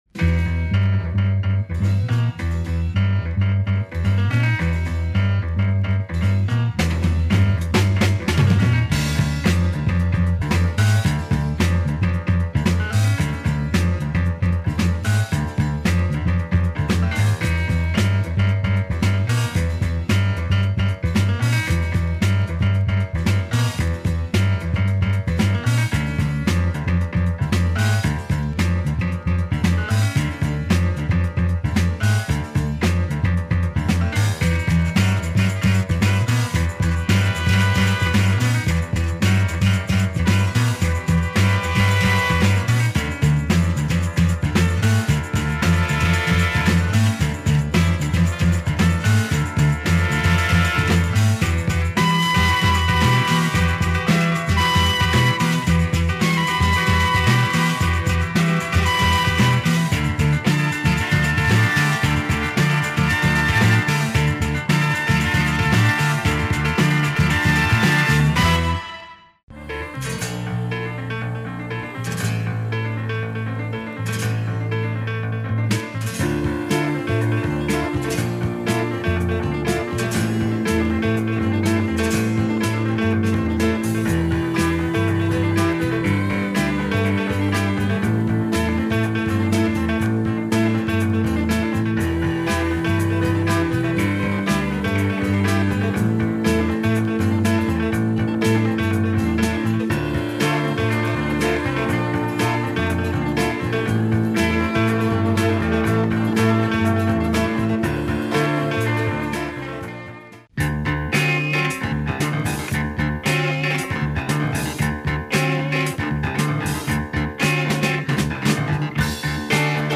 Interesting prog /pop / psychedelic project
have a terrific groovy sound